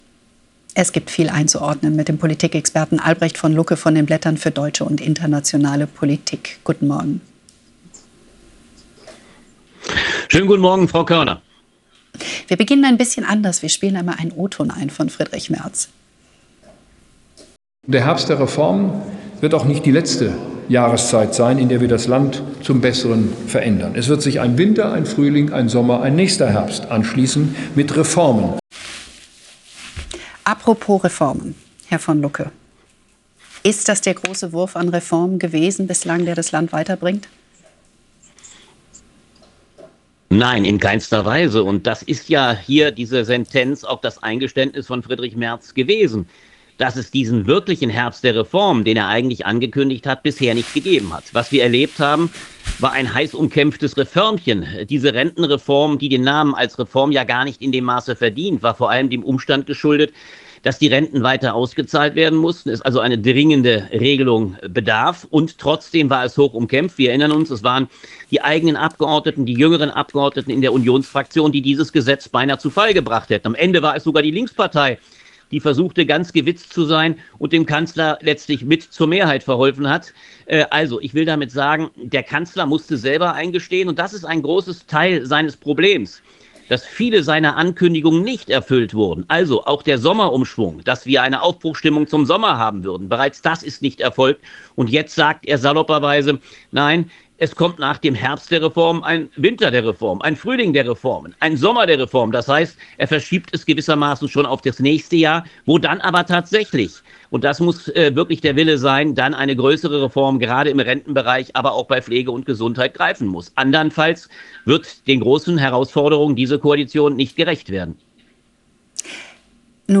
Im Gespräch mit ntv